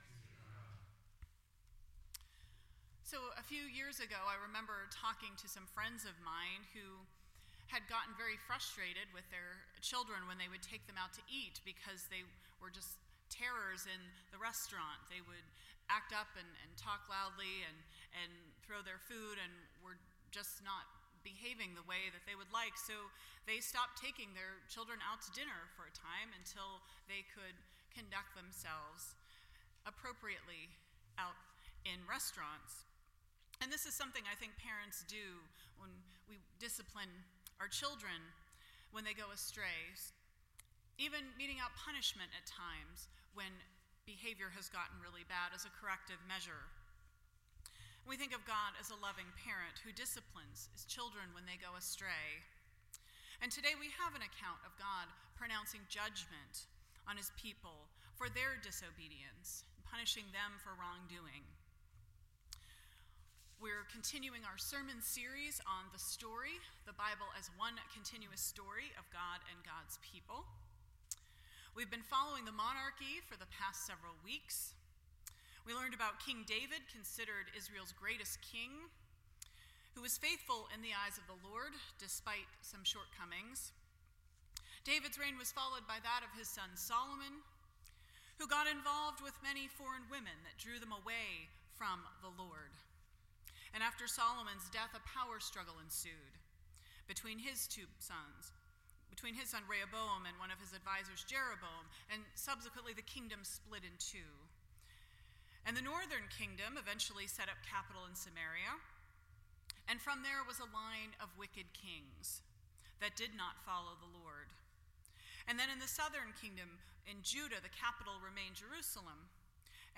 The Story Service Type: Sunday Morning %todo_render% Share This Story